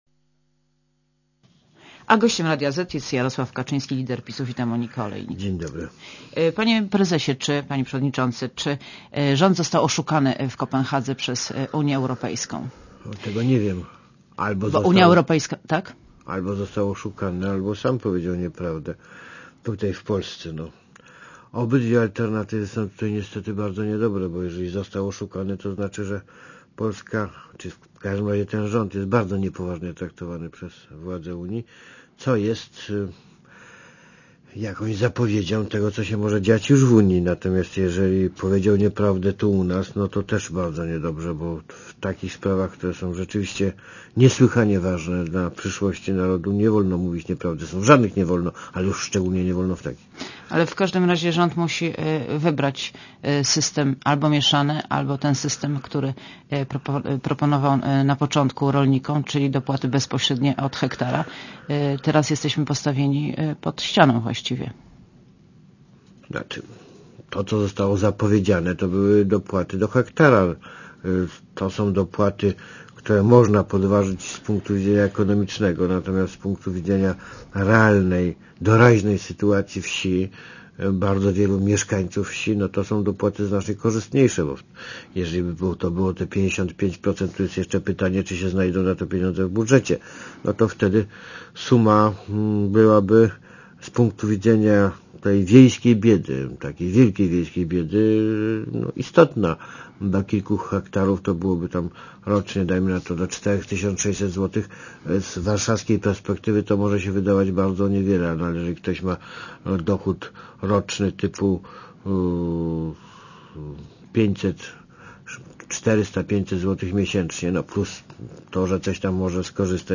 Monika Olejnik rozmawia z Jarosławem Kaczyńskim - liderem PiS Jarosław Kaczyński (PAP)
© (RadioZet) Posłuchaj wywiadu Panie prezesie, panie przewodniczący, czy rząd został oszukany w Kopenhadze przez Unię Europejską?